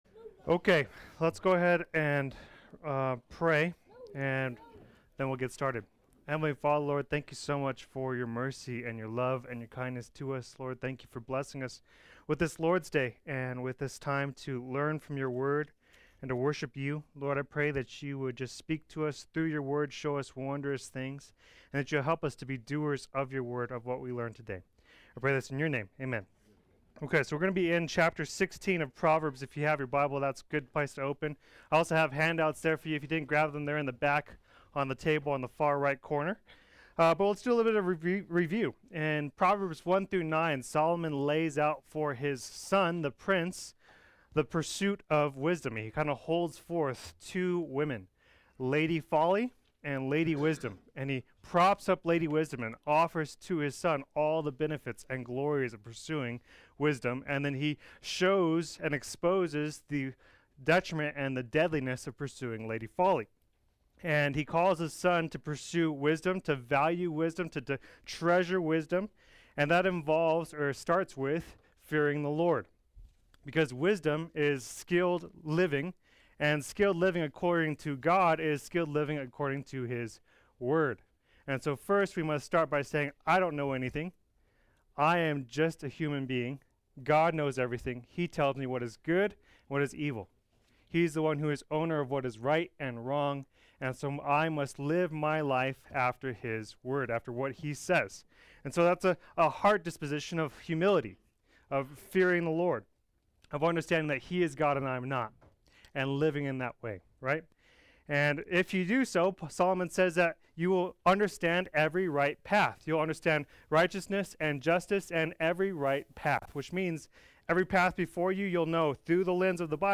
Play Sermon Get HCF Teaching Automatically.
Part 3 Adult Sunday School